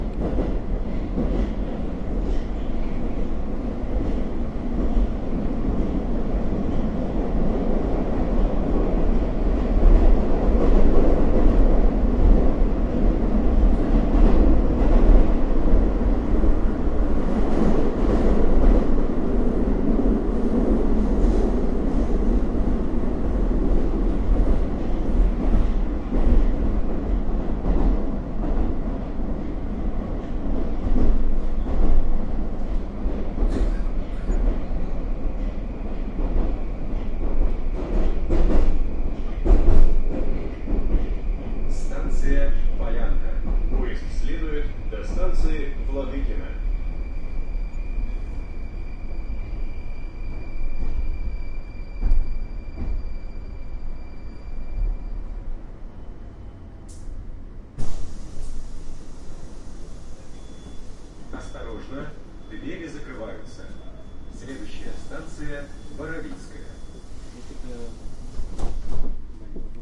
打嗝 3
描述：一个人打嗝
标签： 录音 全方位 麦克风 音频 工作室 声音
声道立体声